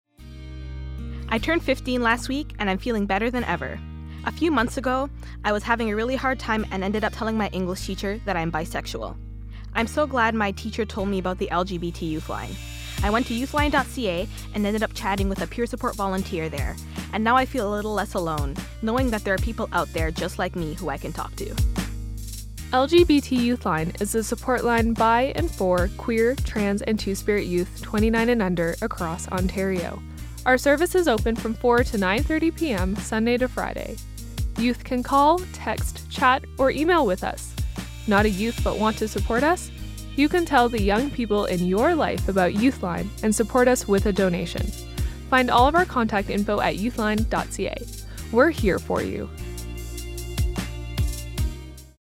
Type: PSA
192kbps Stereo